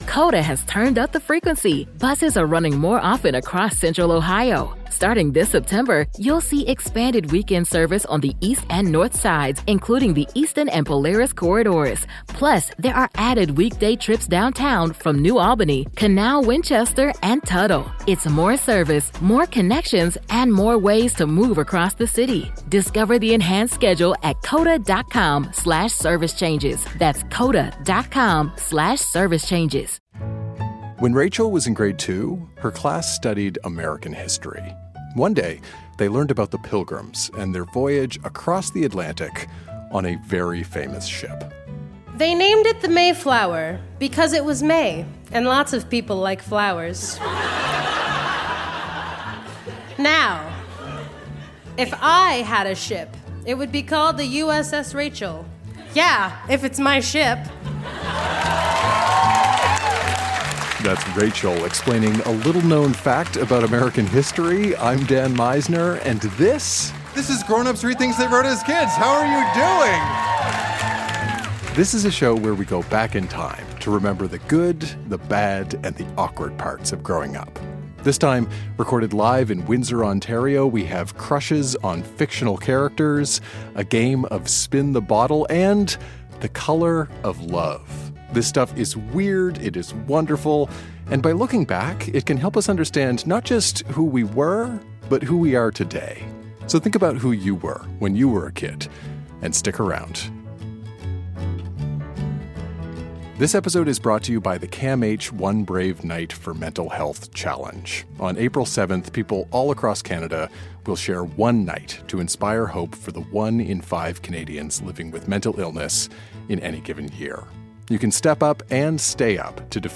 Recorded live at The Rondo in Windsor, ON.